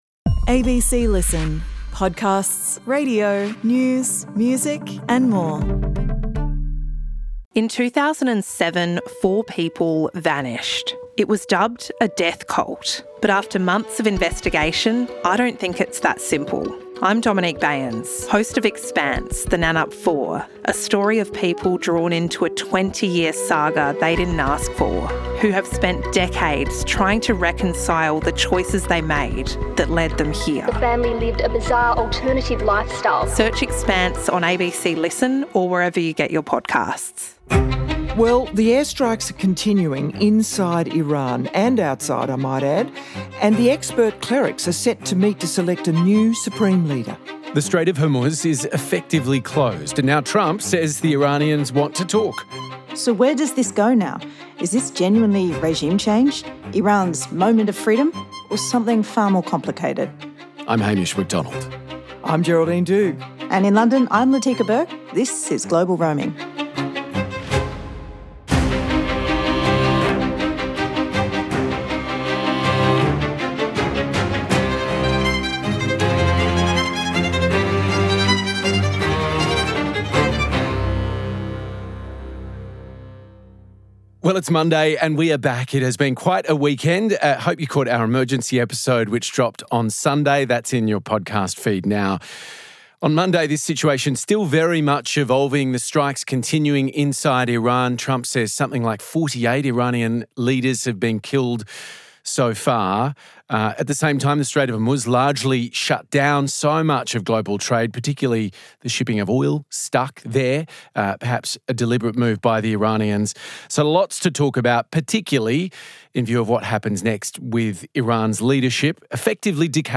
are joined by two guests to unpack the extraordinary attacks against Iran.